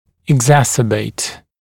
[ɪg’zæsəbeɪt] [eg-][иг’зэсэбэйт] [эг-]усугублять, обострять, ухудшать, усиливать